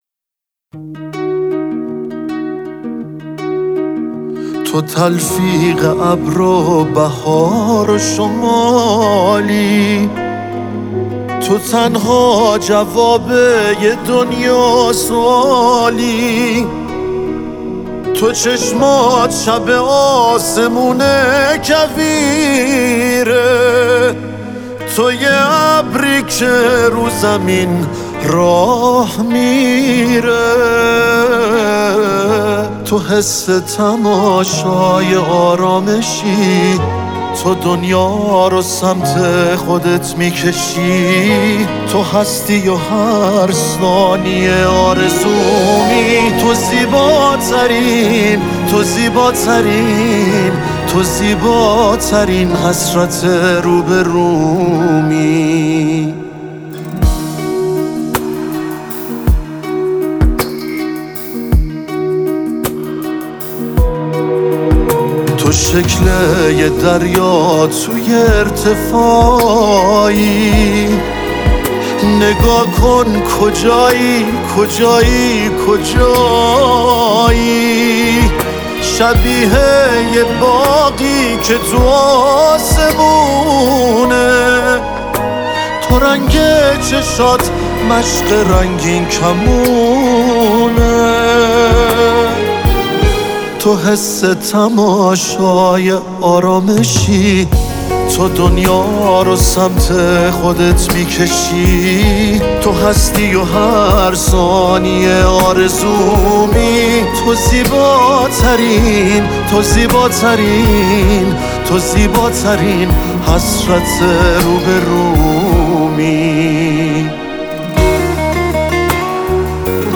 یه مدل عاشقانه دلنشین